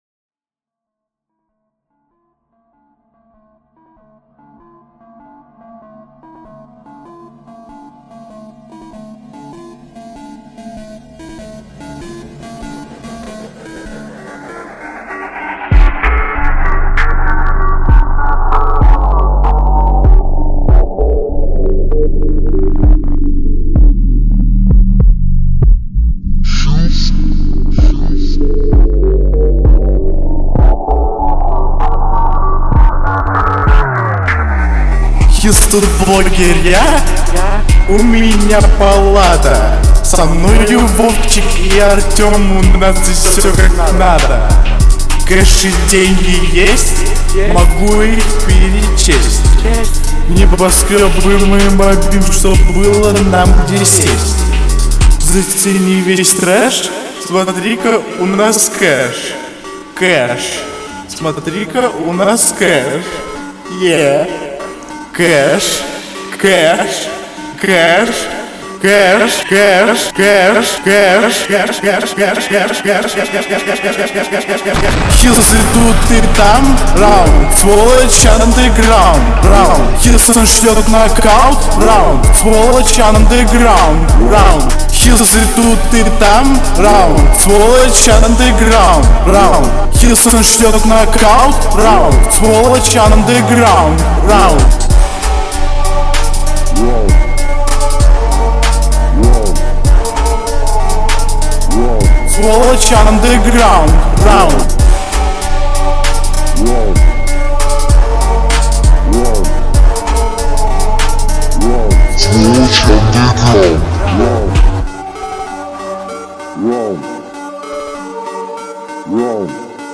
Rap Hip-hop Instrumental Industrial